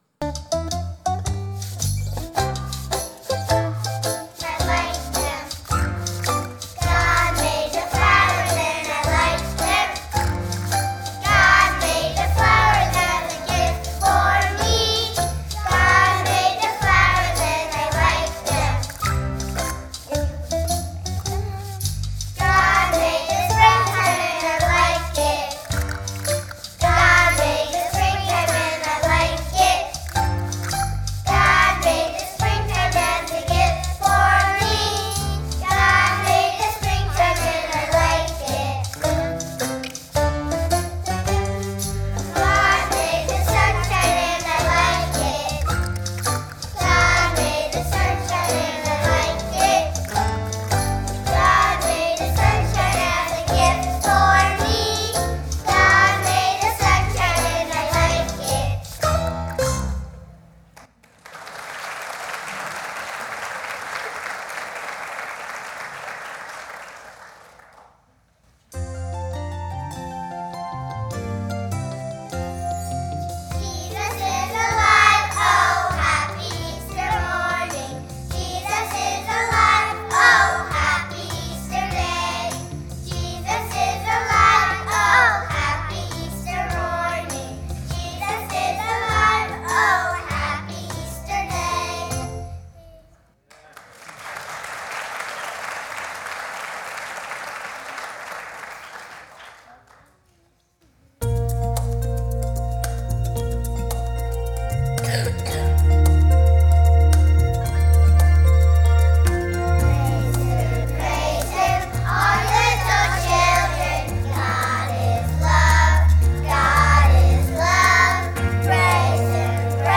Sunday Morning Music
Kindergarten Choir